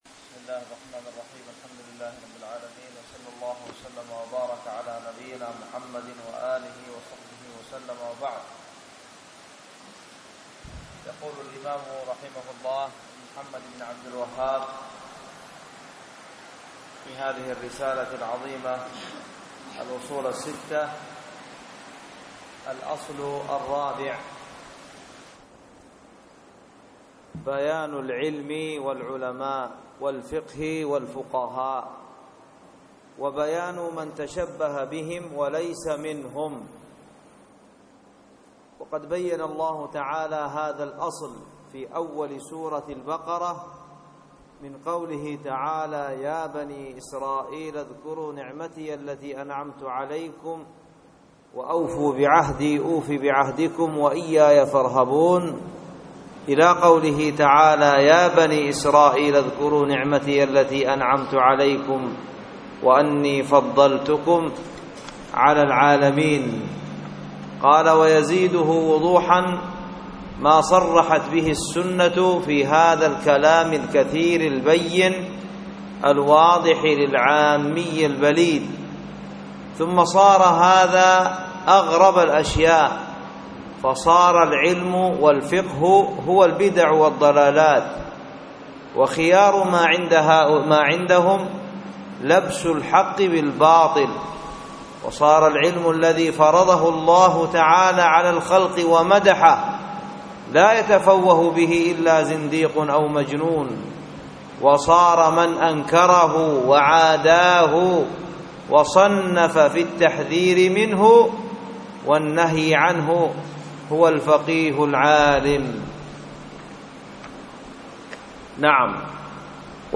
Al-Usoolu Al-Siththa Lesson 4.mp3